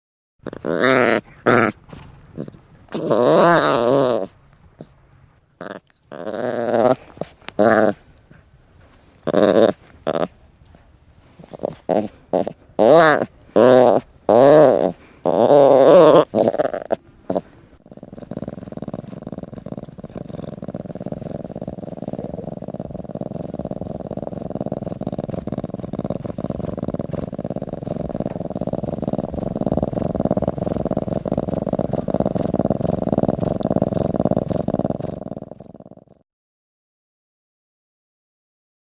lynxaud.mp3